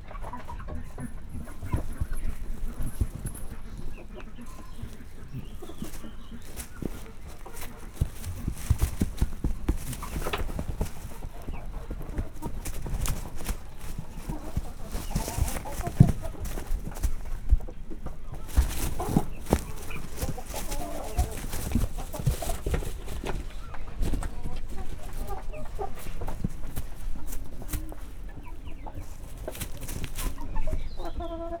szalmabanmaszkal_baromfi00.31.wav